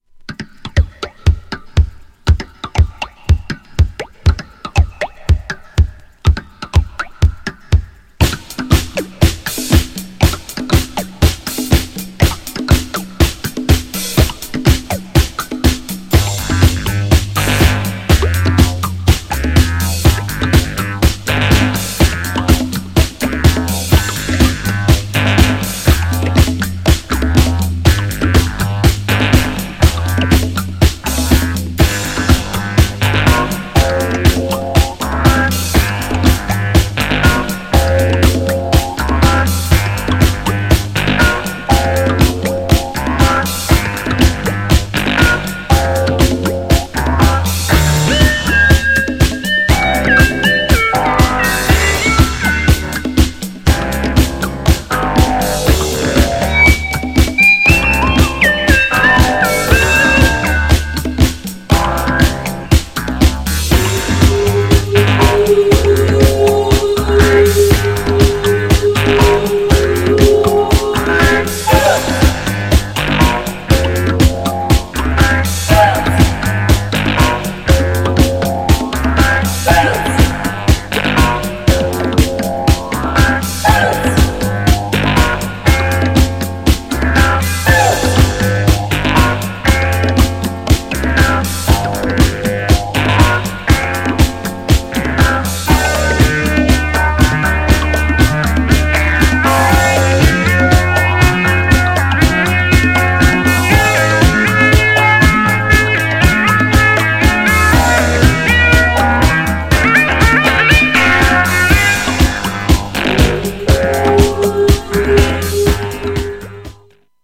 B面も人気!!
GENRE Dance Classic
BPM 96〜100BPM